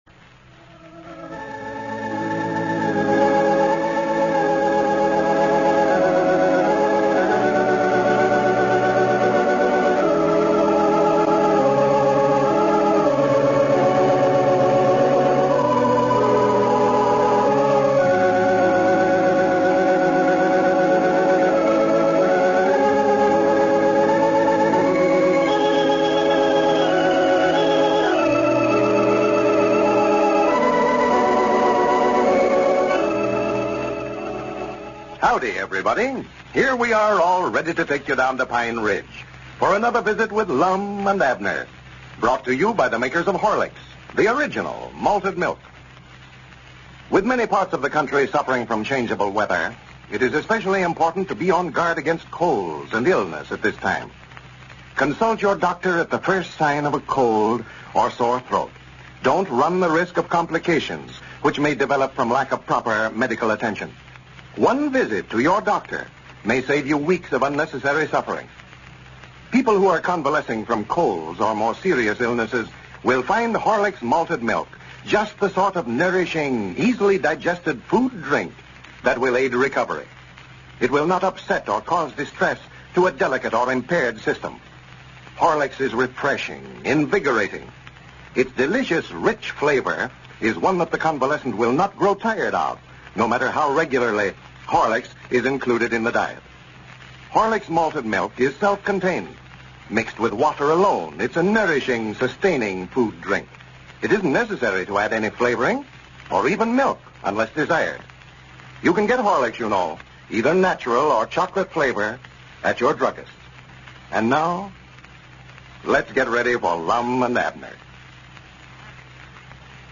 A classic radio show that brought laughter to millions of Americans from 1931 to 1954.